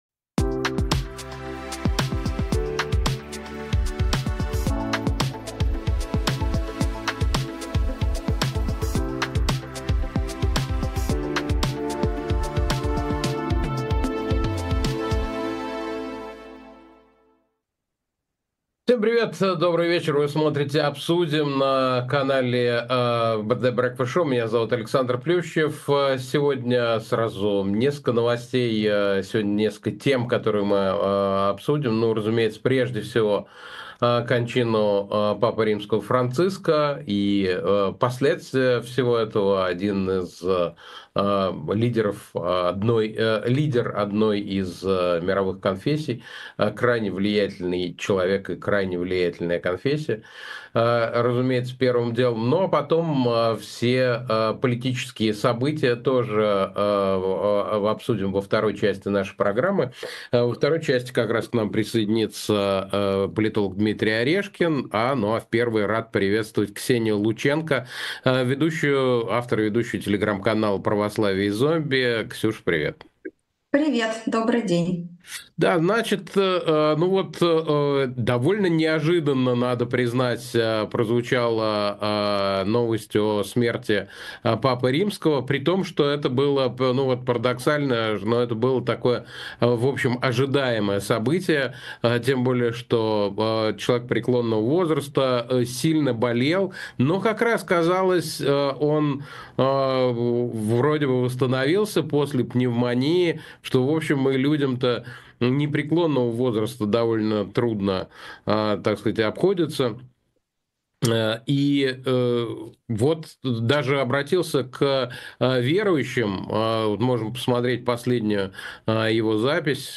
Ведёт эфир Александр Плющев